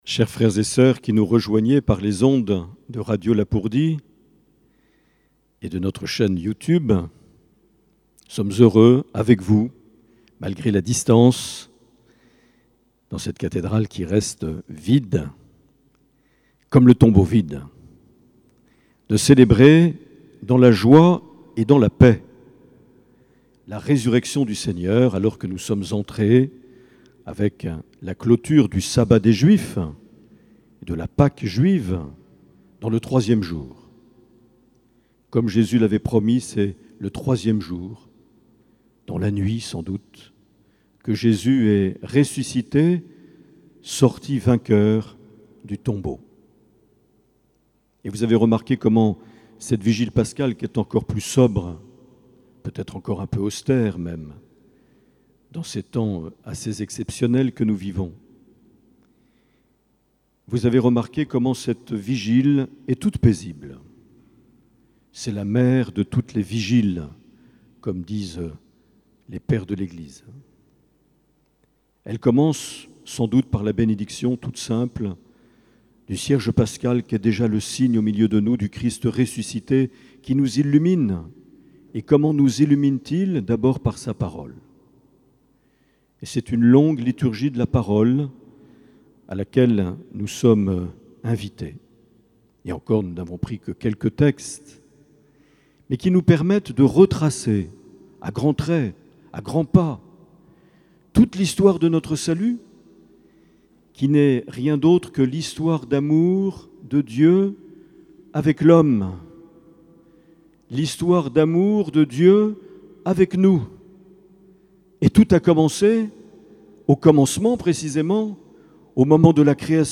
Les Homélies
Une émission présentée par Monseigneur Marc Aillet